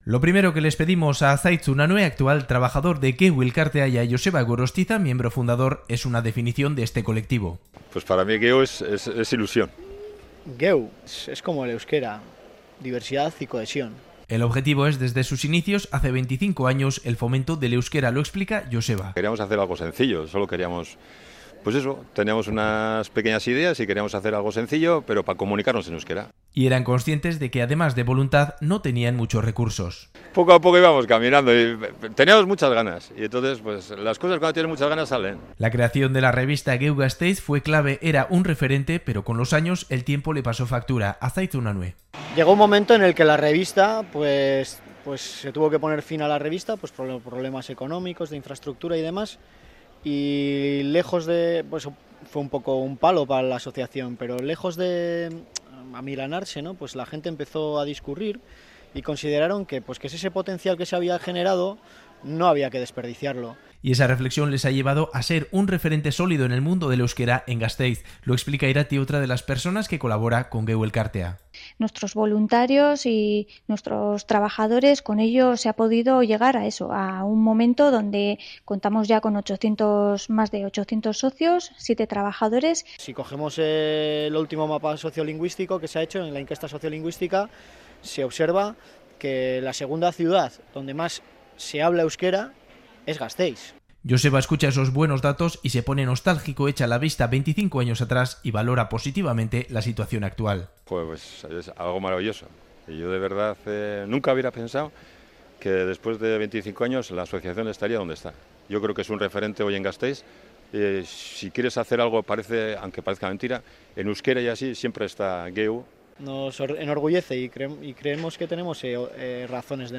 Radio Euskadi REPORTAJES Un colectivo clave en el desarrollo del euskera en Vitoria-Gasteiz Última actualización: 16/11/2017 10:38 (UTC+1) El euskera avanza notablemente en las últimas décadas en Álava, pero especialmente en Vitoria-Gasteiz. El conocimiento del idioma ha crecido notablemente y su uso en las calles también va a más.